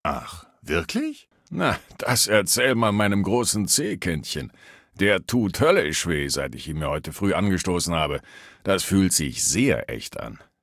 Sep. 2025 Maleadult02 mq04 mq04residentsimulatio 00095b3b.ogg (Datei)
97 KB Charakter: Bill Foster Kategorie:Fallout 3: Audiodialoge 1